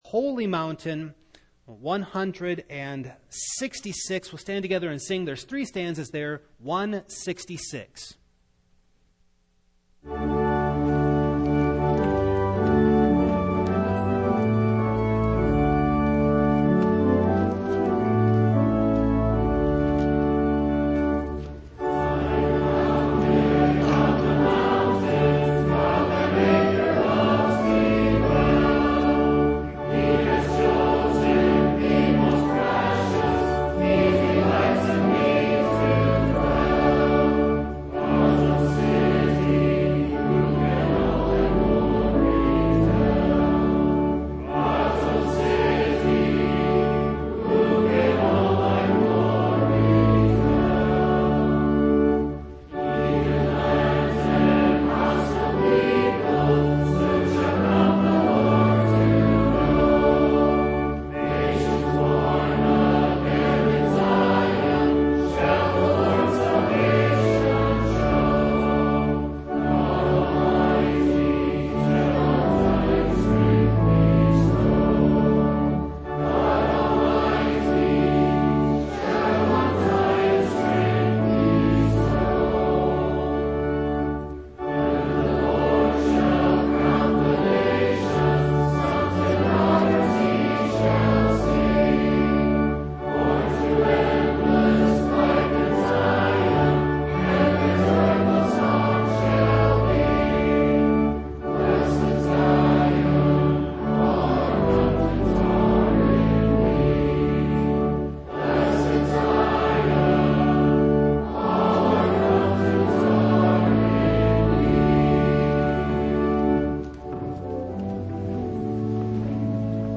Passage: Matthew 16:13-20 Service Type: Evening